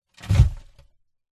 Глухой стук земли и камней о крышку гроба, одиночное погребение